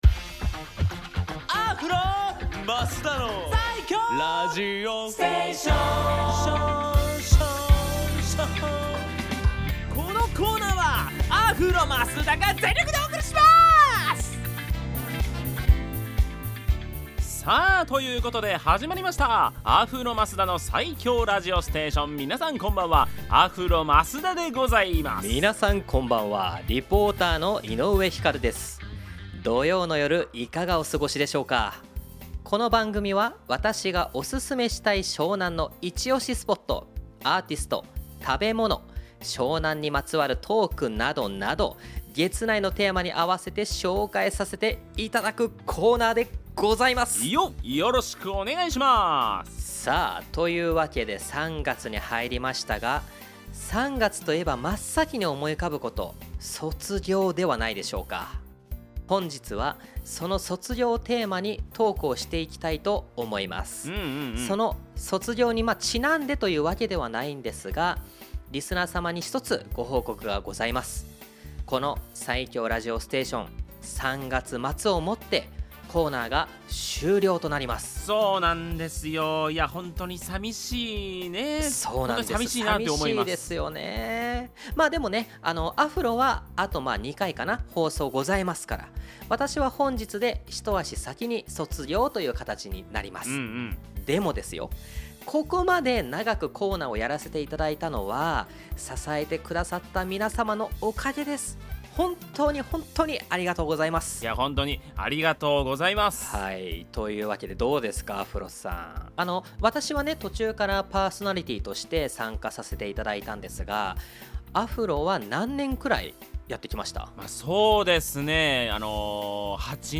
こちらの放送音源をお届けします♪